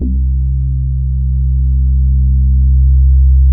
04BASS01  -R.wav